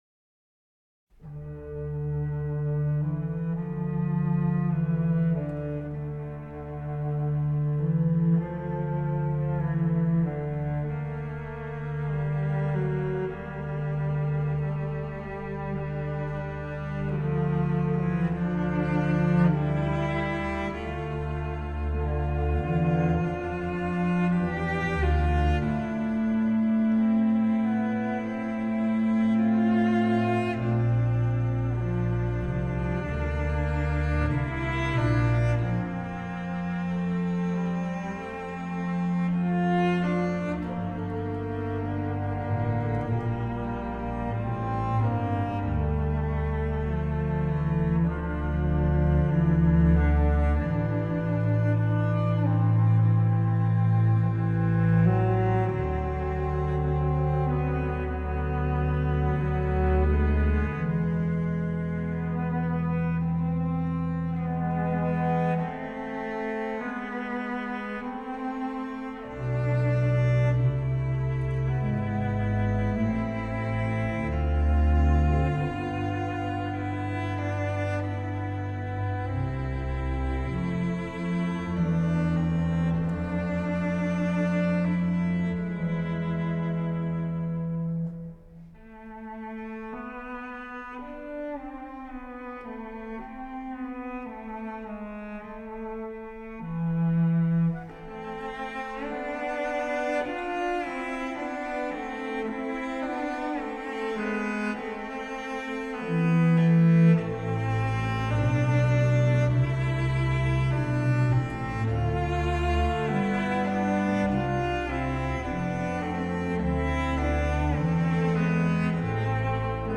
Handel, Organ Concertos, Op. 7 Nos. 4 – 6, 099 Concert F major Op. 7_4 D – Adagio